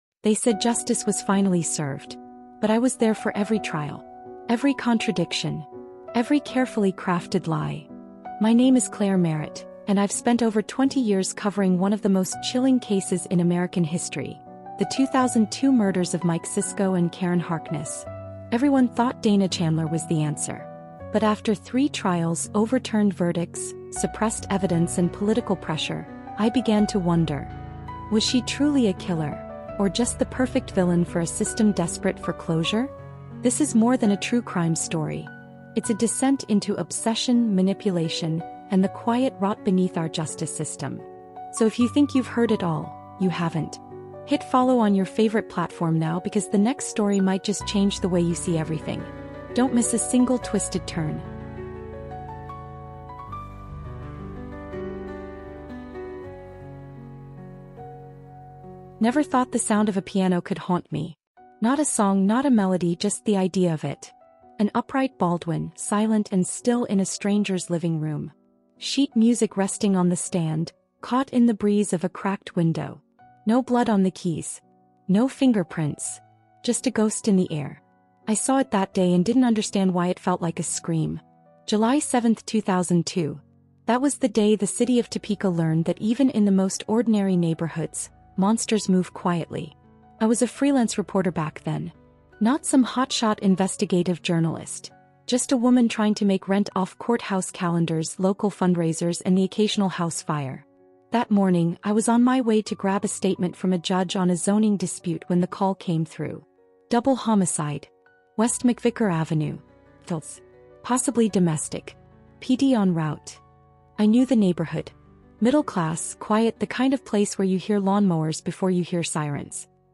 a gripping, emotionally immersive true crime audiobook documentary told from the first-person perspective of a veteran journalist